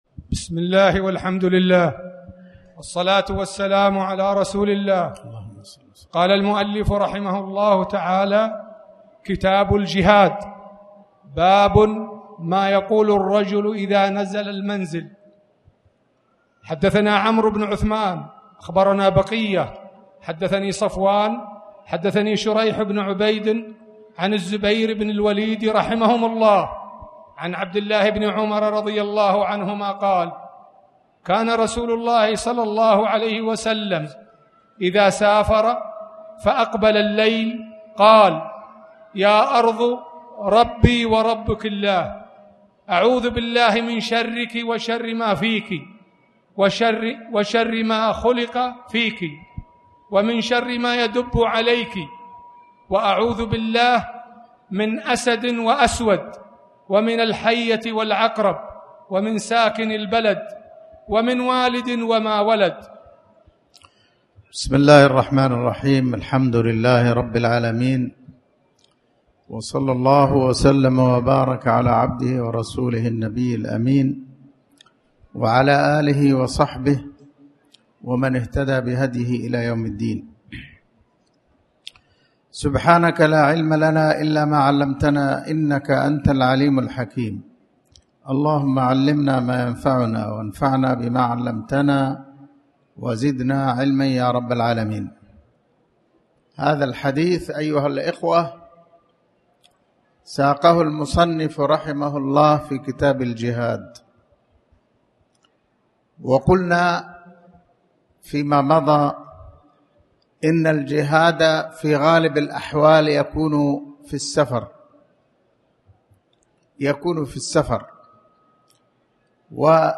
تاريخ النشر ٢٤ محرم ١٤٣٩ هـ المكان: المسجد الحرام الشيخ